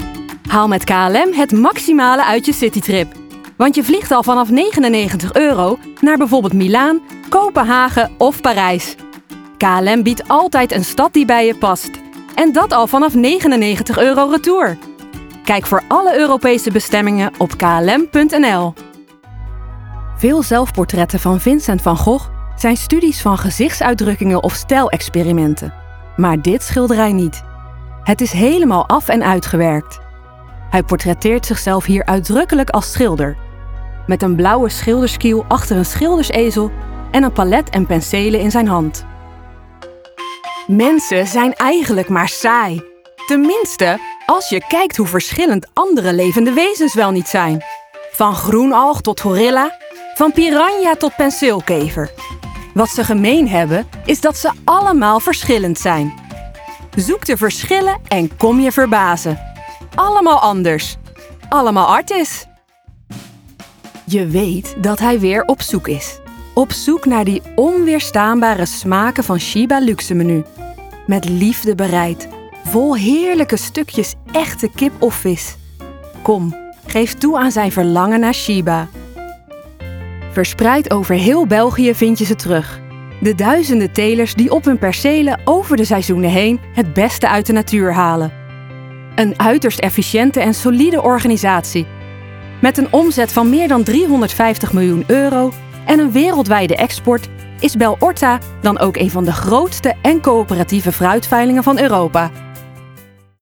stemdemo